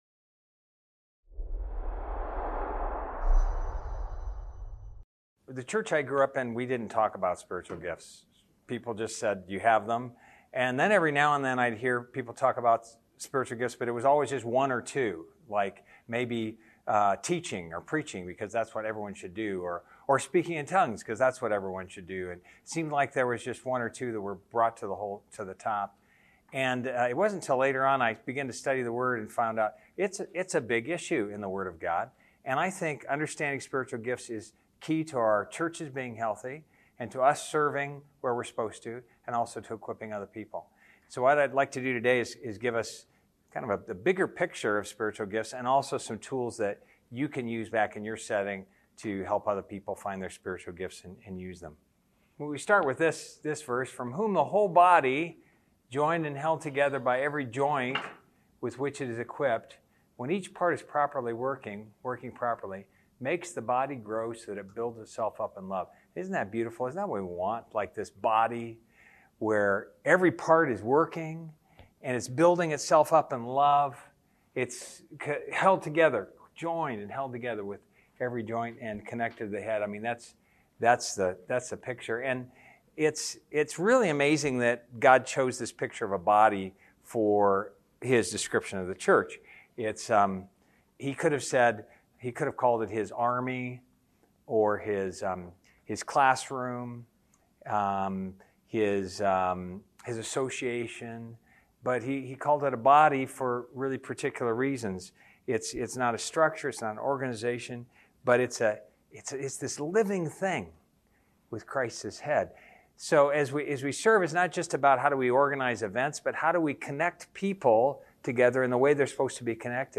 Event: ELF Workshop